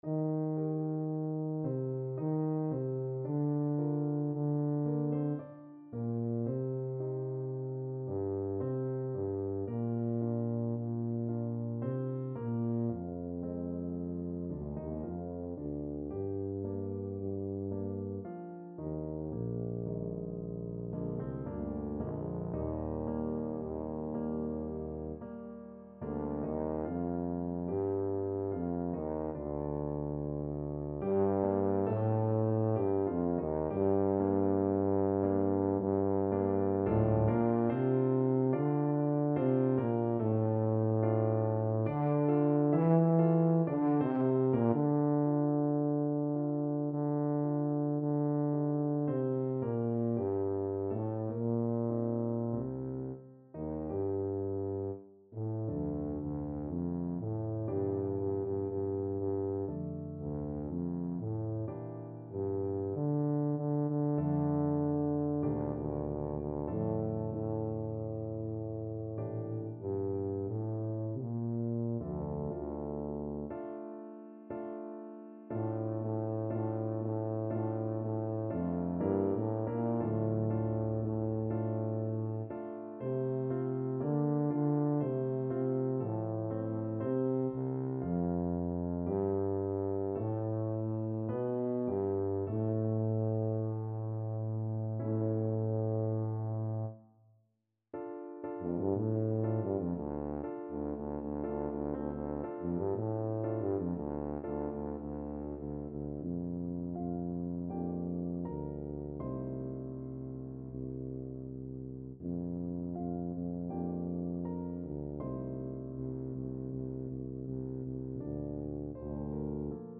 3/4 (View more 3/4 Music)
Andante molto calmo = 56
Classical (View more Classical Tuba Music)